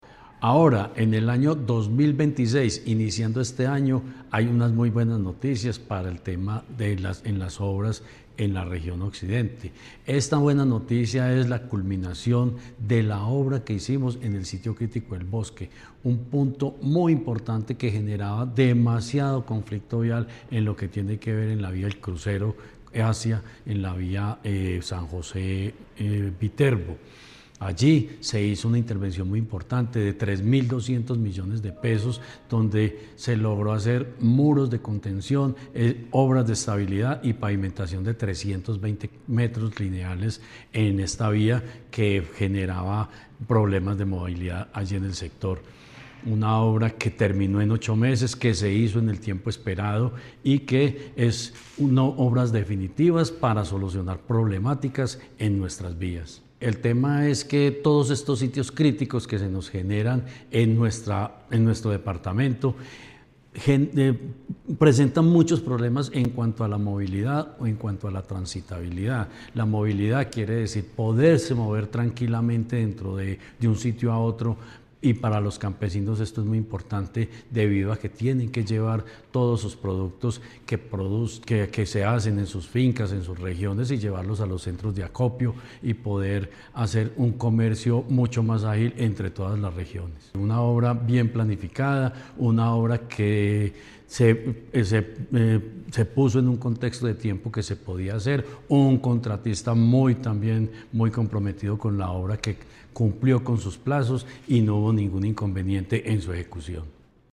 Secretario de Infraestructura de Caldas, Jorge Ricardo Gutiérrez Cardona.